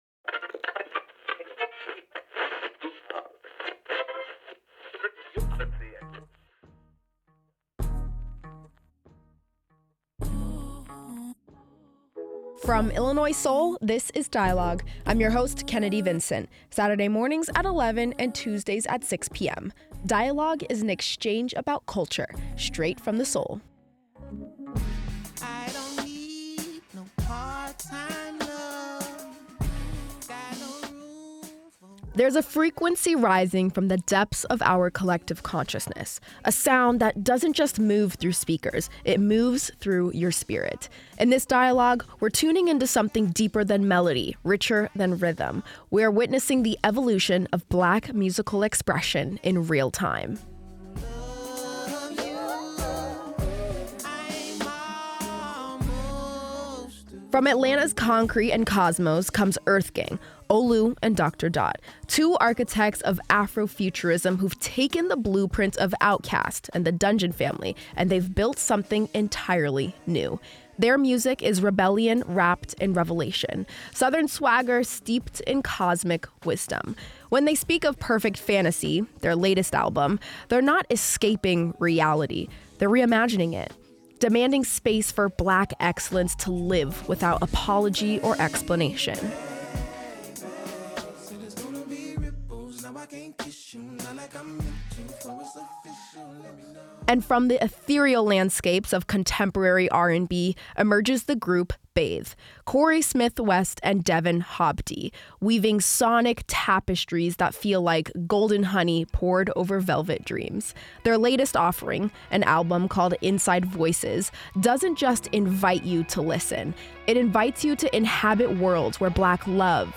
This year two groups not only graced the stage with their presence but also sat down with Dialogue to use their voices and amplify the message of their music.